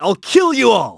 Riheet-Vox_Skill5.wav